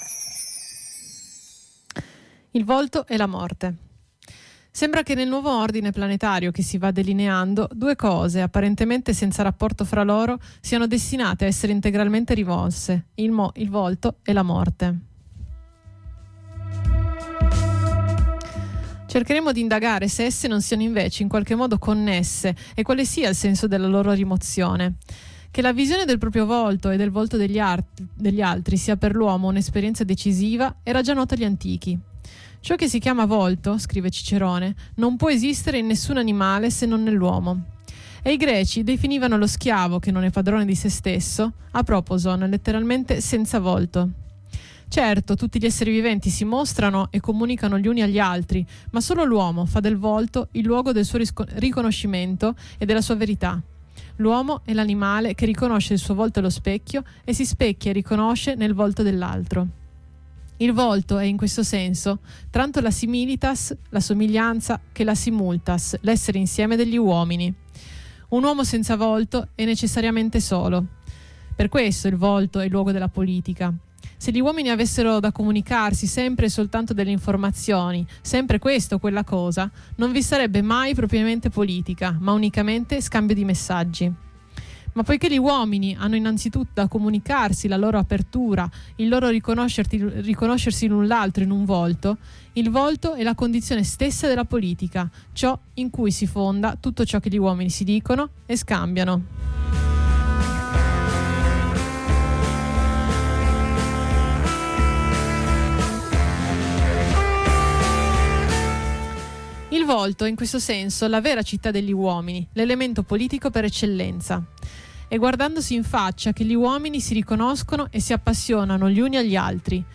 Lettura da Giorgio Agamben “Il volto e la morte ” :